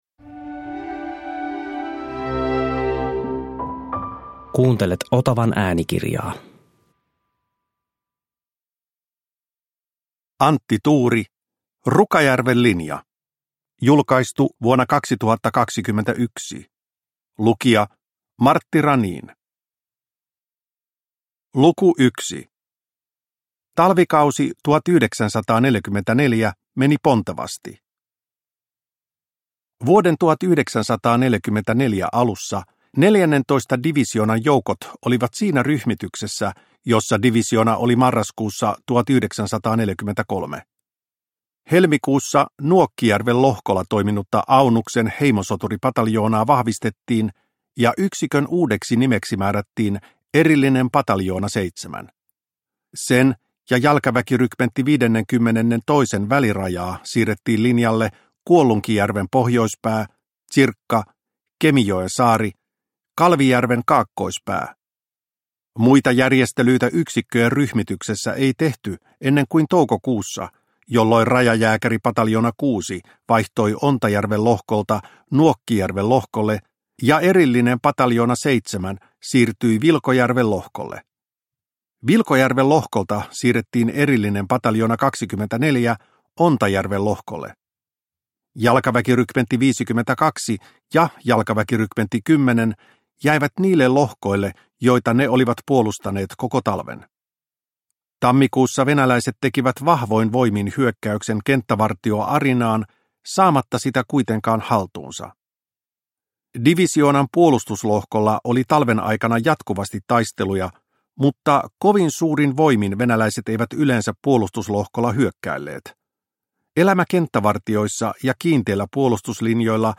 Rukajärven linja – Ljudbok – Laddas ner